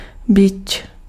Ääntäminen
France: IPA: [œ̃ fwɛ]